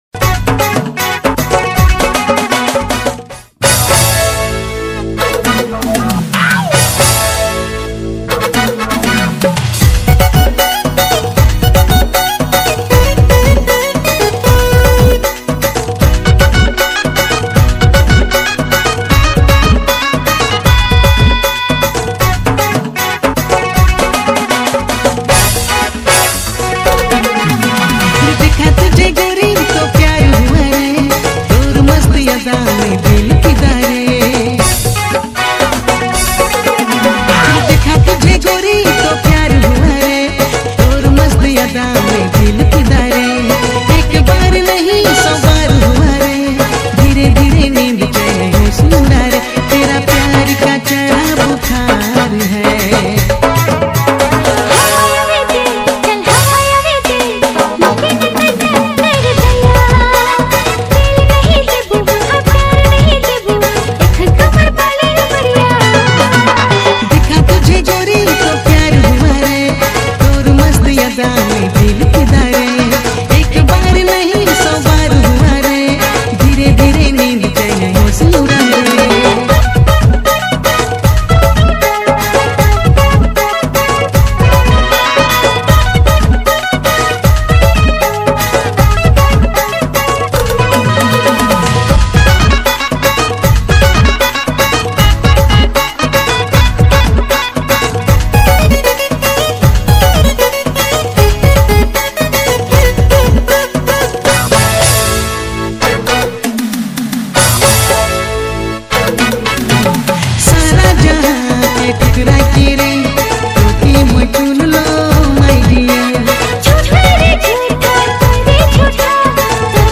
New Nagpuri Dj Songs Mp3 2025